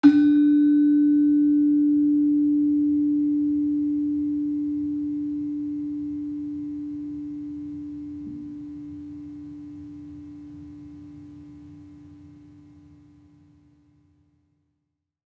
Gender-3-D3-f.wav